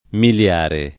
miliare
vai all'elenco alfabetico delle voci ingrandisci il carattere 100% rimpicciolisci il carattere stampa invia tramite posta elettronica codividi su Facebook miliare [ mil L# re ] (antiq. migliare [ mil’l’ # re ]) agg.